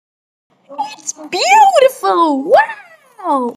Beautiful Wow Sound Button - Free Download & Play